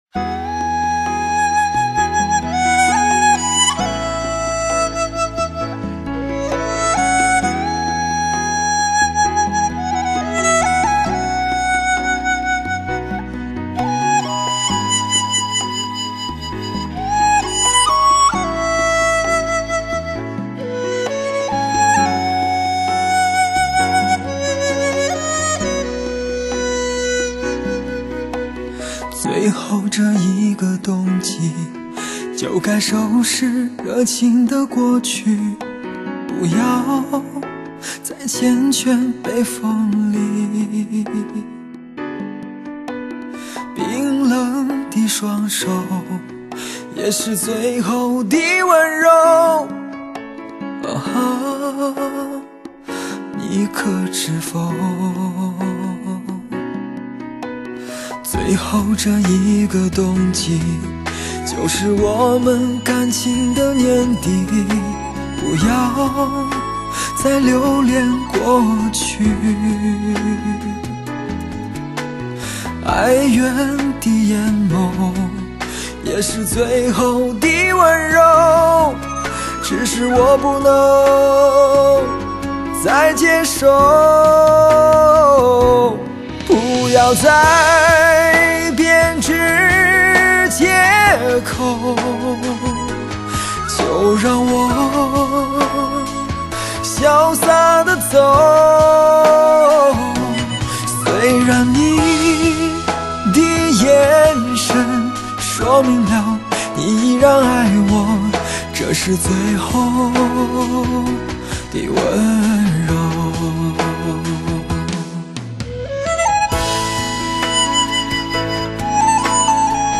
现代发烧深情代表作，首席疗伤音乐男声魅力。
本世纪最真实的声音，无可挑剔最深情的男声。
抒发心灵放松的解药，抚慰心灵的疗伤音乐，内心情感世界的告白。